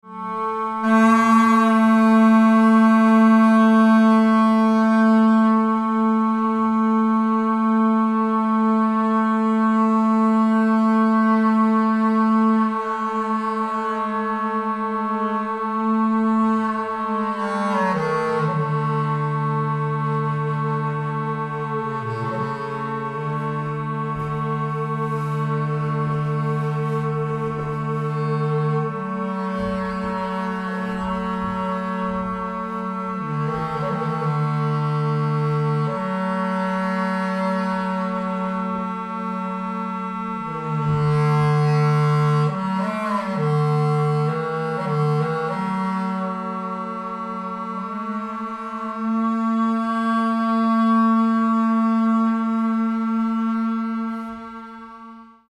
Winter Solstice
Oakland California